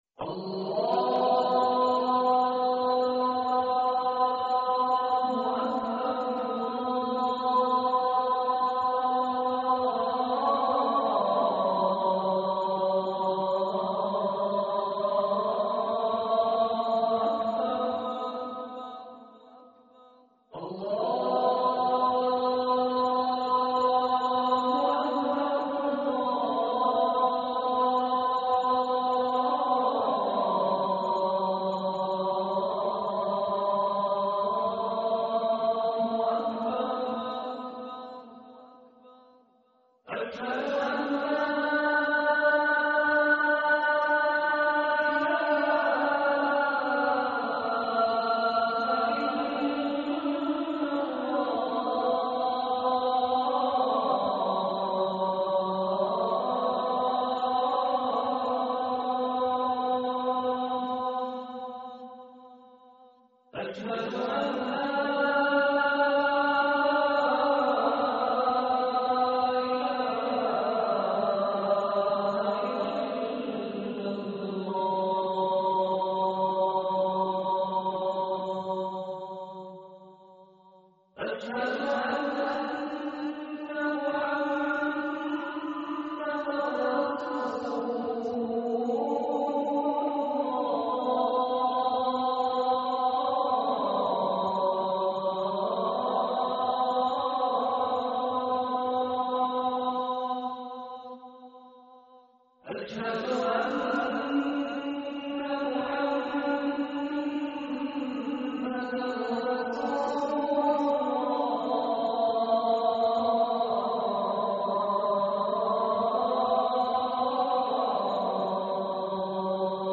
أناشيد ونغمات
عنوان المادة أذان الفجر2_الشيخ مشاري بن راشد العفاسي